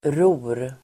Uttal: [ro:r]